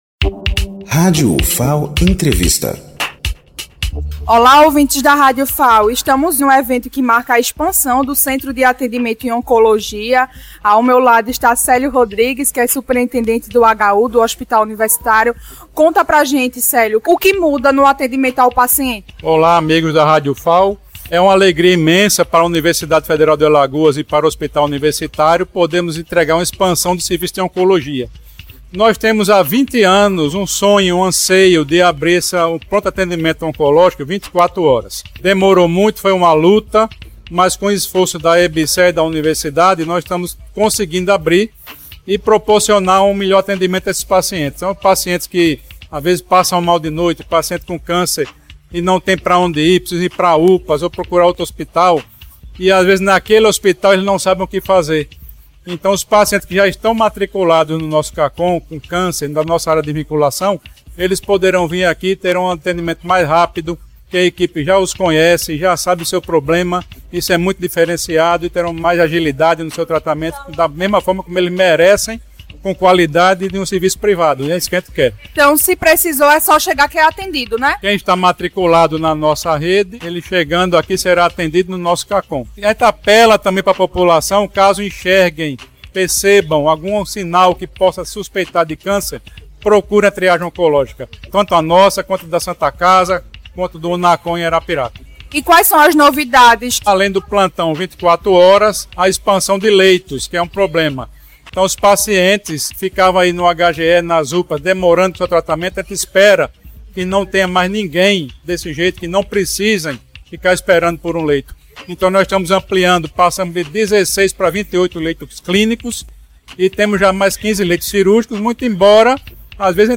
UfalEntrevistaHU.mp3